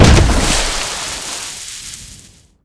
land_on_water_2.wav